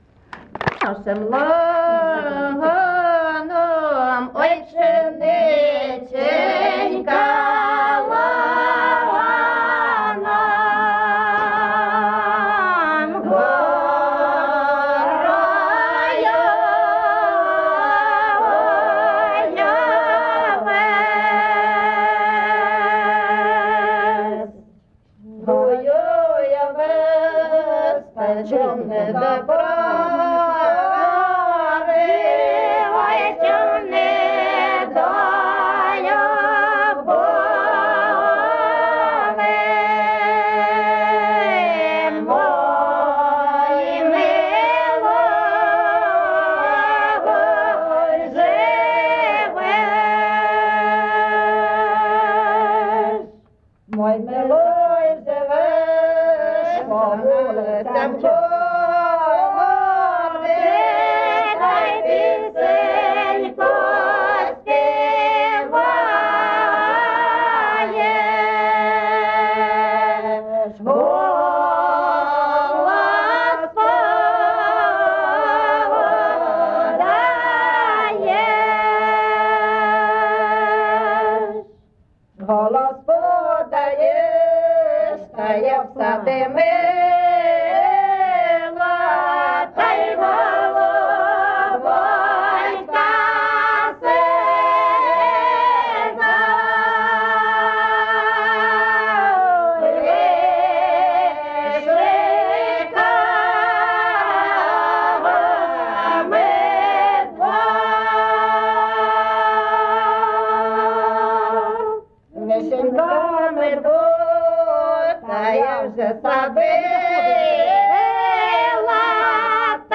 ЖанрПісні з особистого та родинного життя
Місце записус. Писарівка, Золочівський район, Харківська обл., Україна, Слобожанщина